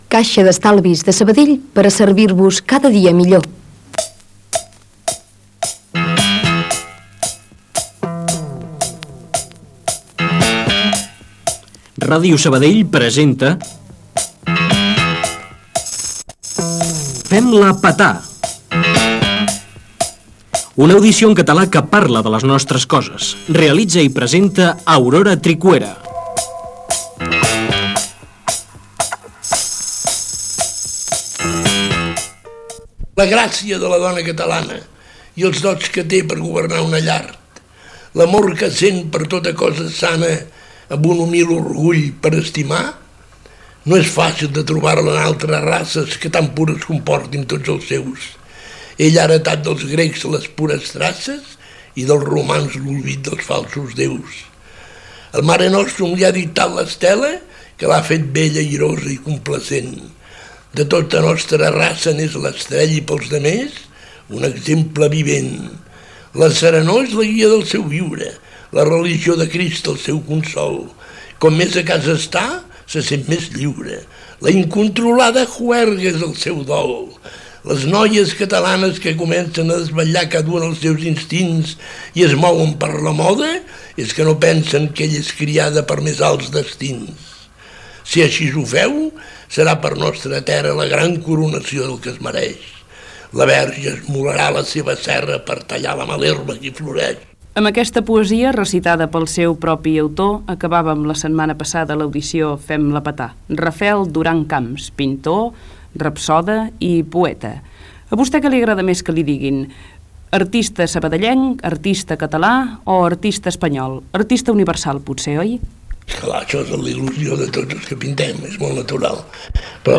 Publicitat, careta del programa, recitat d'una poesia i entrevista
Careta de sortia del programa i publicitat